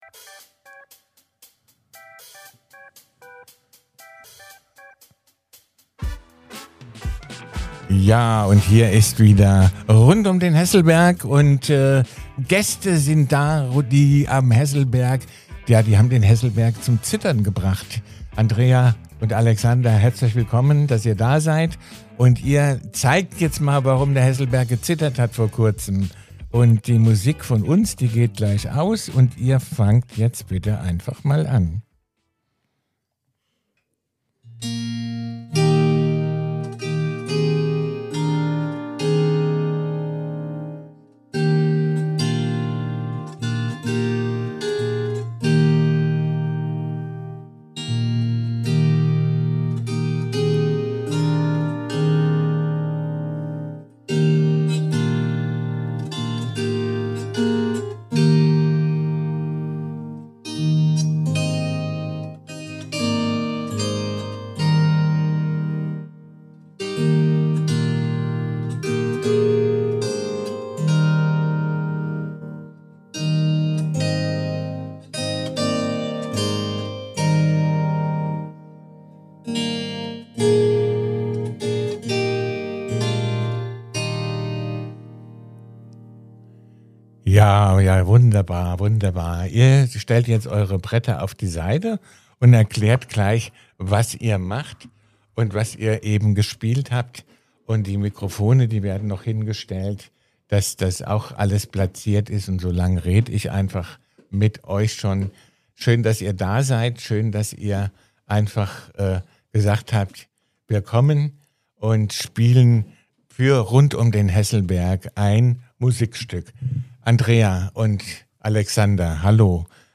Freut euch auf inspirierende Geschichten, musikalische Einlagen und einen authentischen Blick hinter die Kulissen unserer musikalischen Gemeinschaft.